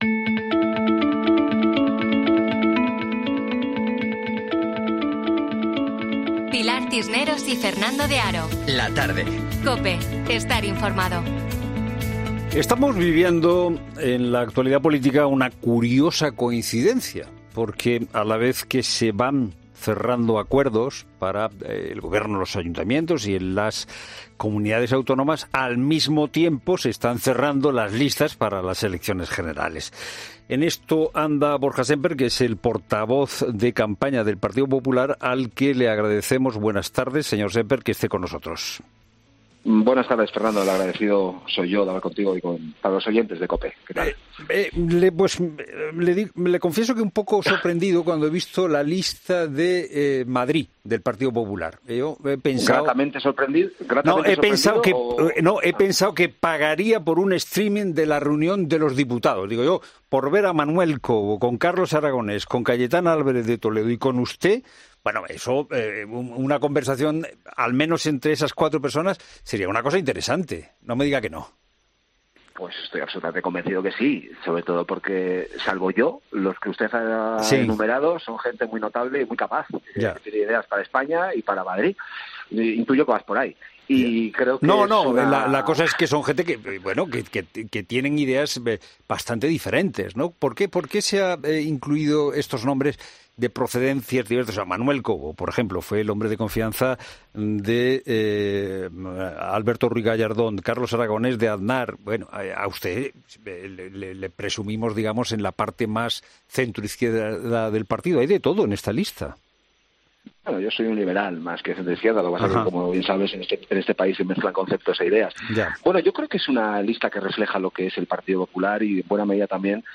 El portavoz de campaña del Partido Popular ha asegurado en COPE que el objetivo de la formación a partir próximo 23-J es conformar un gobierno "solo...